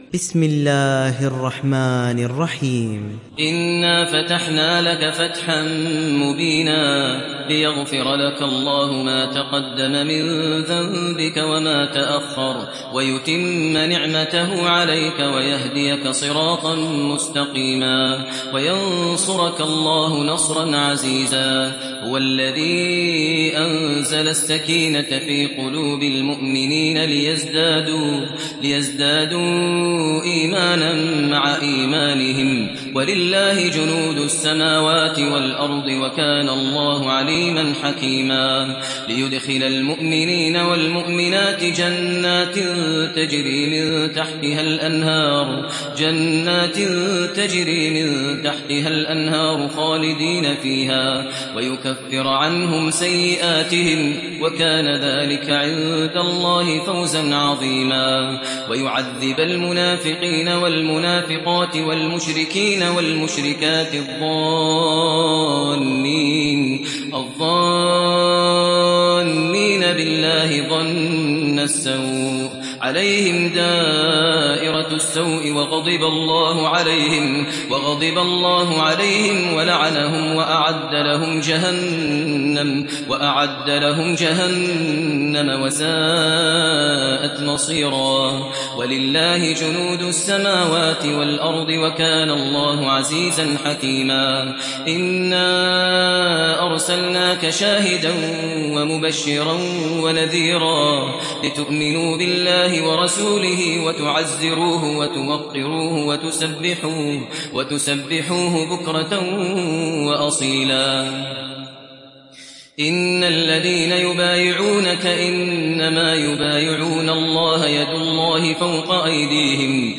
Surat Al Fath mp3 Download Maher Al Muaiqly (Riwayat Hafs)